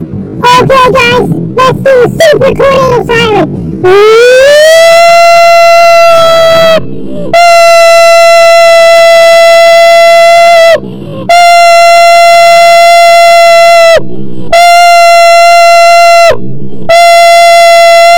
Tornado Siren High Pitched Human - Botão de Efeito Sonoro